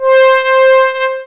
STRINGY.wav